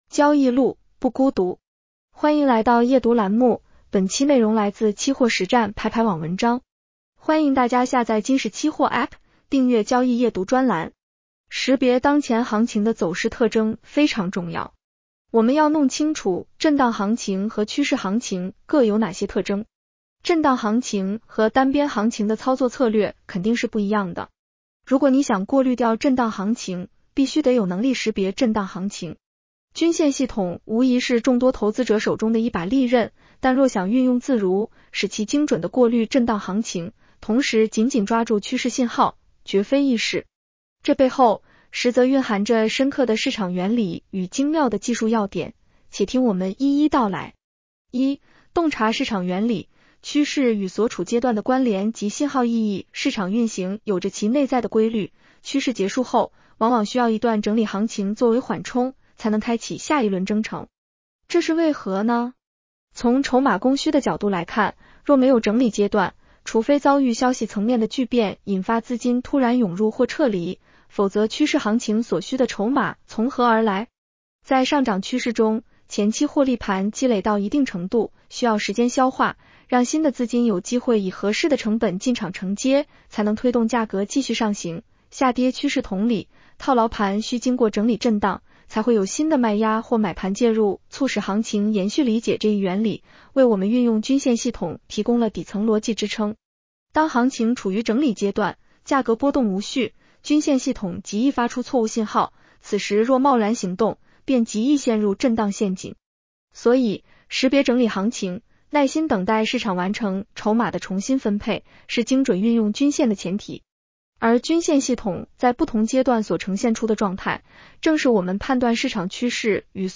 【期货交易夜读音频版】 女声普通话版 下载mp3 识别当前行情的走势特征非常重要。